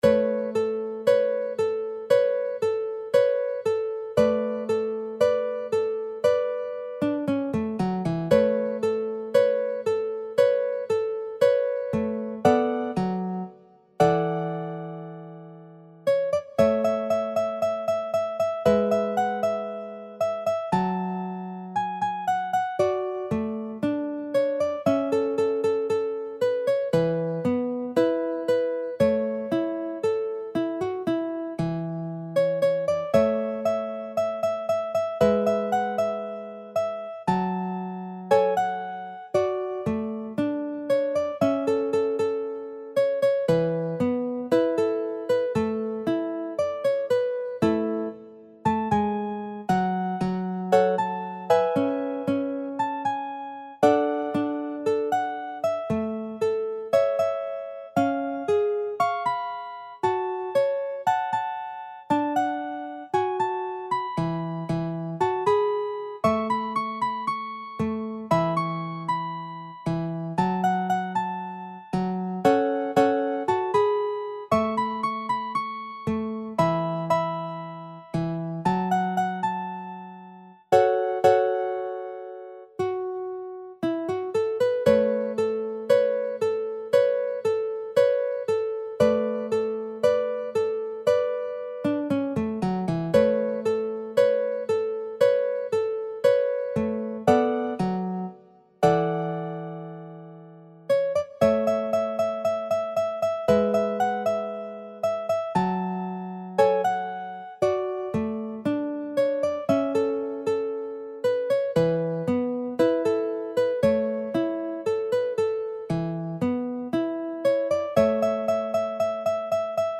J-POP / フォーク・ニューミュージック
楽譜の音源（デモ演奏）は下記URLよりご確認いただけます。
（この音源はコンピューターによる演奏ですが、実際に人が演奏することで、さらに表現豊かで魅力的なサウンドになります！）